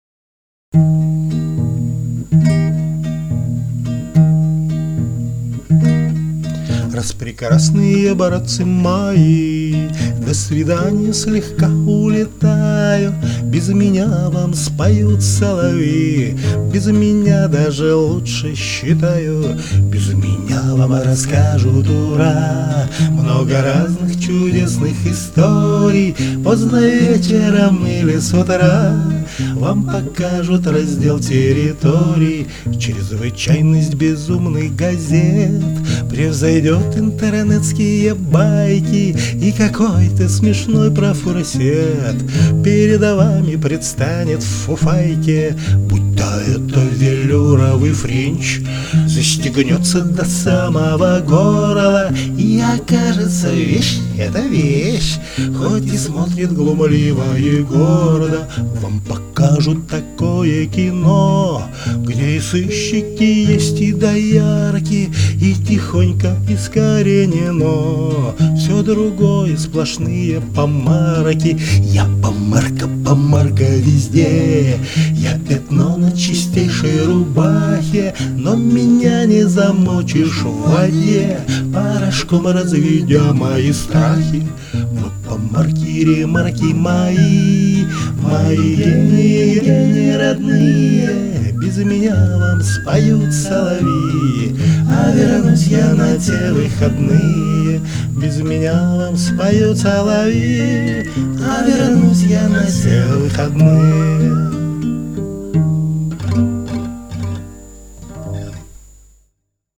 Категория: Красивая музыка » Песни под гитару